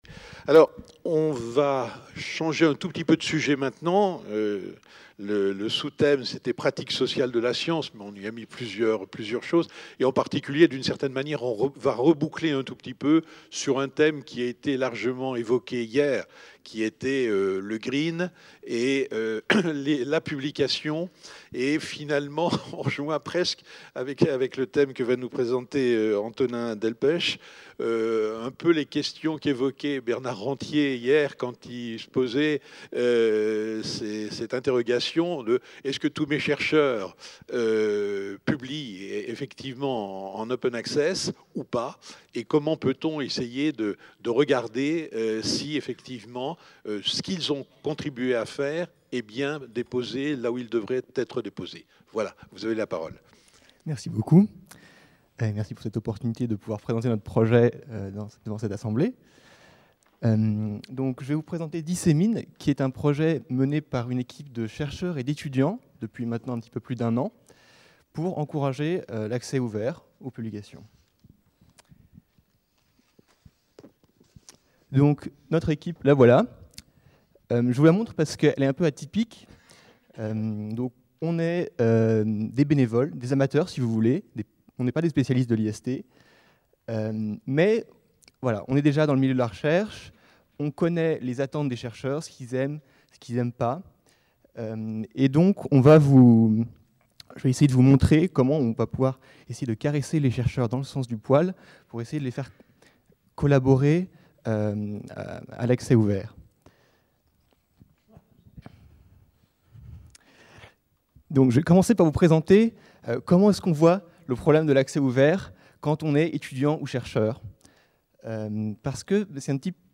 Présentation de Dissemin | Canal U